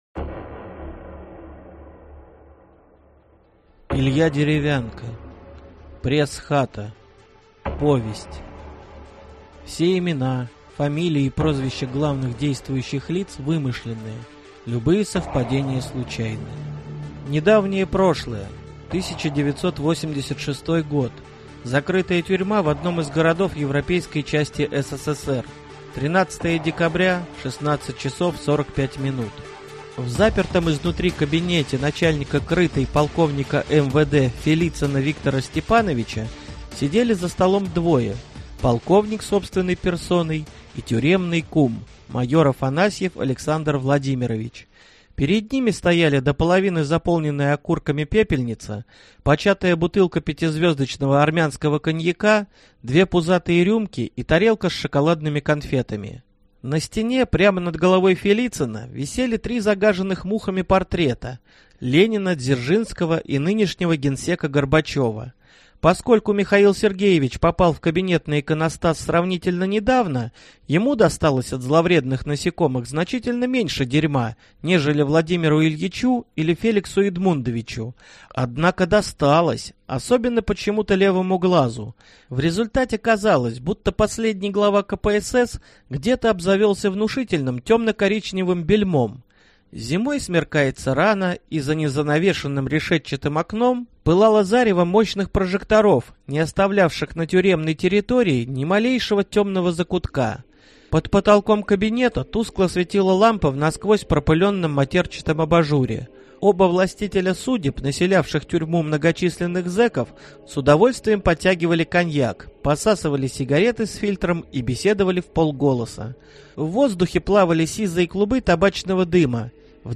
Аудиокнига Пресс-хата | Библиотека аудиокниг
Прослушать и бесплатно скачать фрагмент аудиокниги